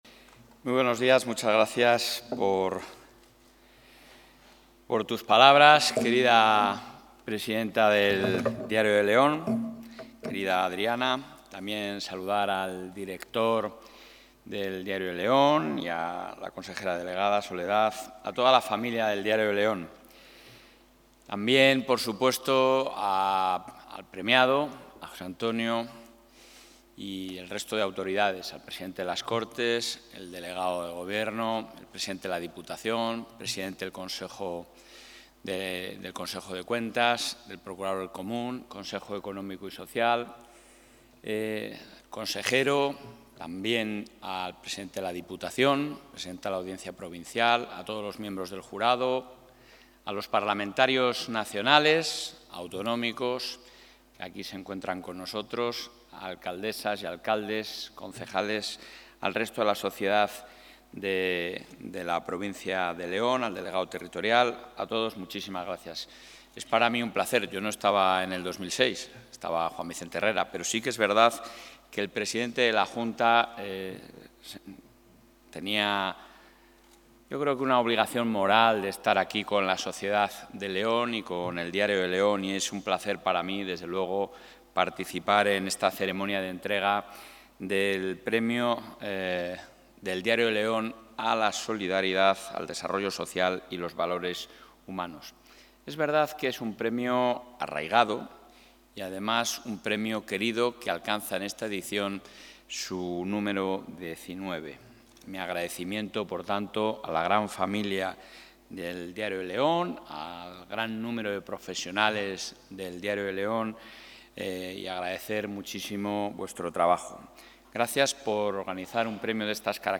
El presidente de la Junta de Castilla y León, Alfonso Fernández Mañueco, ha participado hoy en la ceremonia de entrega del XIX...
Intervención del presidente de la Junta.